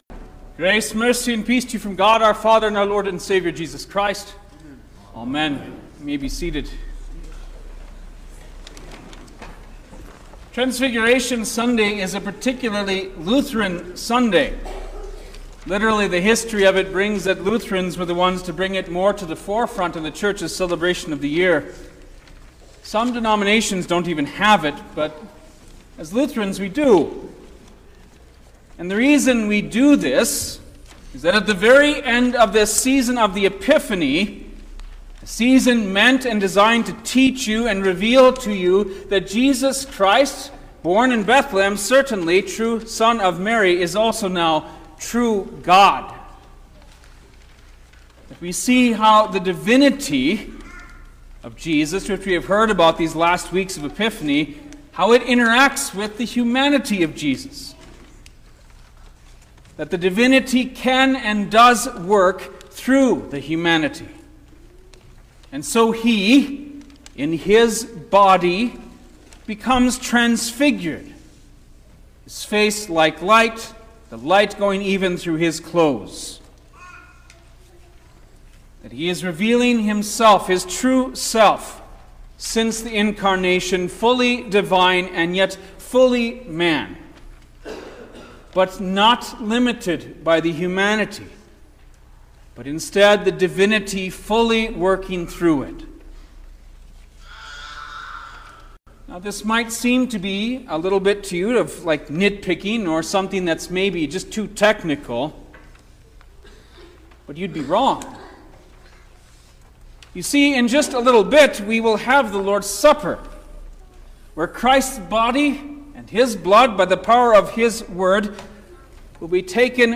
February-9_2025_The-Transfiguration-of-Our-Lord_Sermon-Stereo.mp3